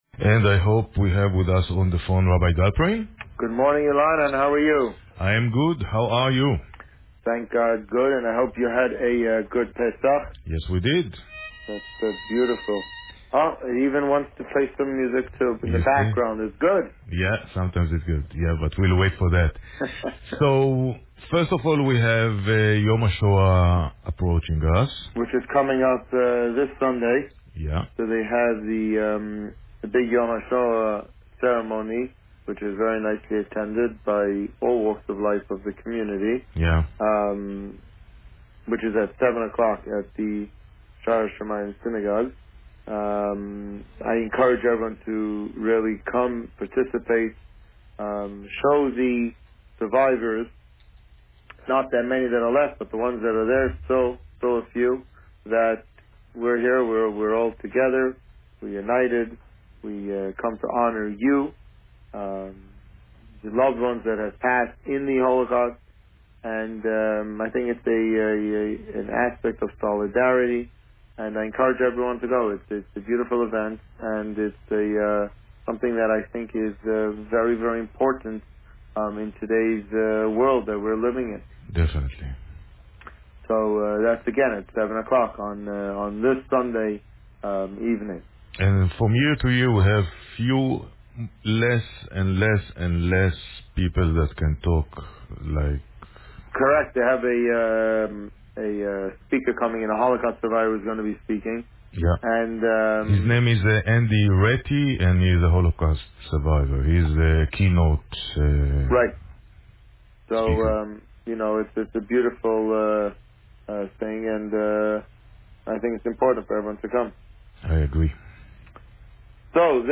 This week, the Rabbi covered Parasha Shemini and this Sunday's upcoming Yom Hashoah celebrations. Listen to the interview here.